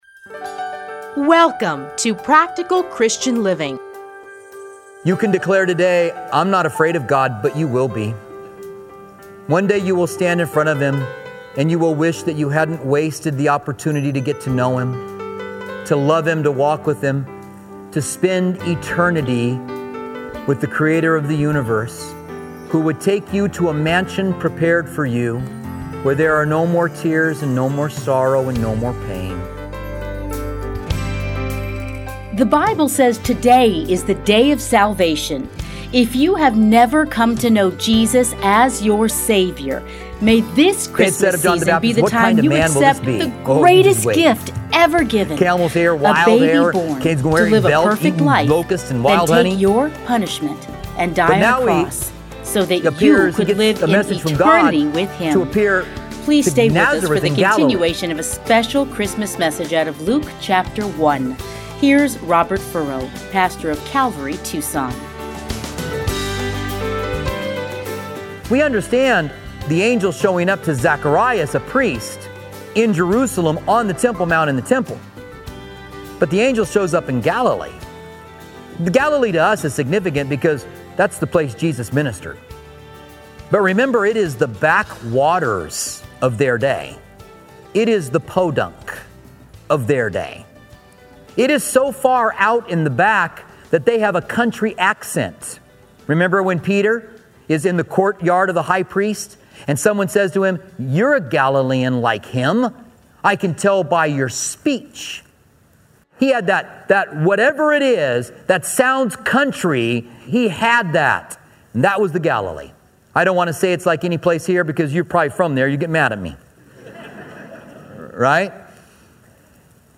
Listen here to a special Christmas message.